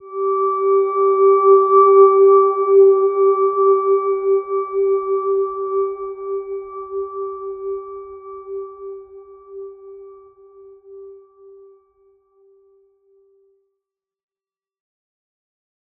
Silver-Gem-G4-mf.wav